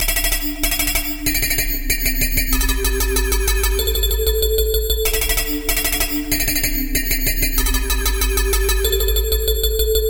它开始的时候是一个漂亮的调制钟声，但后来我觉得我加了很多音符，它就有点疯了。它甚至听起来都不像钟声了！
Tag: 95 bpm Weird Loops Fx Loops 1.70 MB wav Key : Unknown